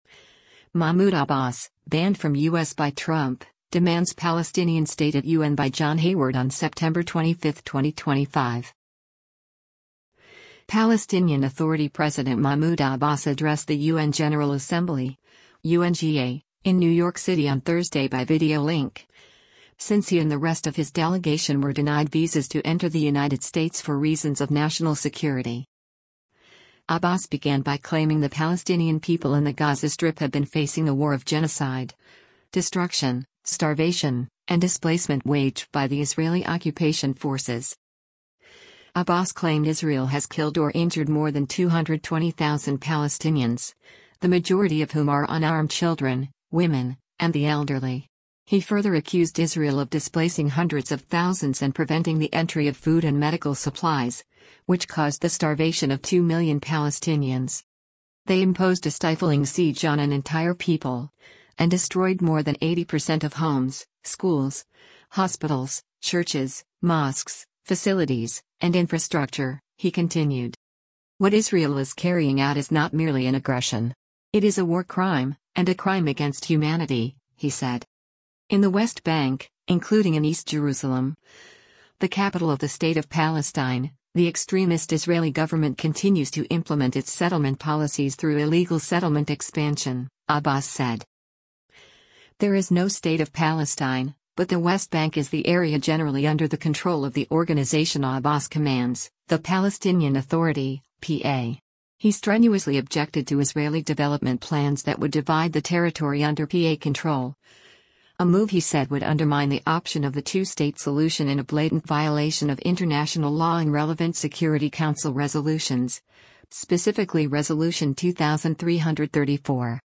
Palestinian Authority President Mahmoud Abbas addressed the U.N. General Assembly (UNGA) in New York City on Thursday by video link, since he and the rest of his delegation were denied visas to enter the United States for reasons of national security.